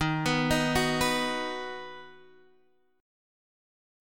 D#m#5 chord